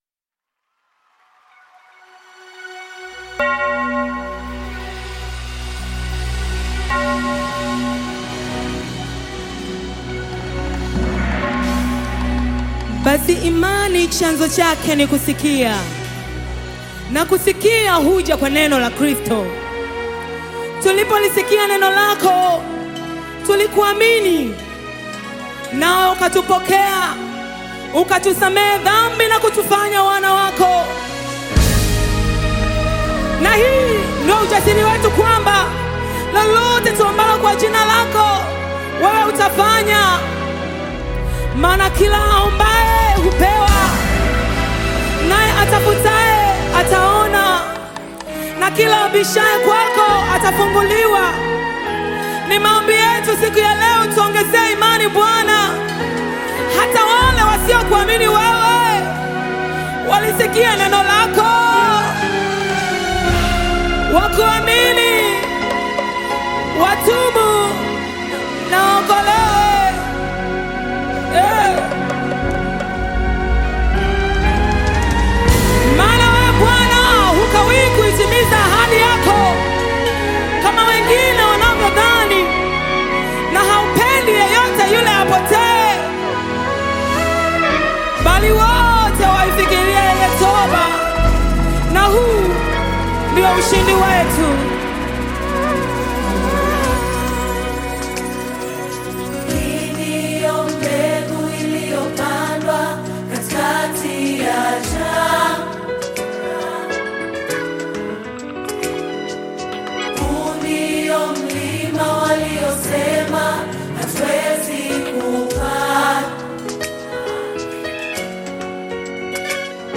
Tanzanian gospel group
Gospel song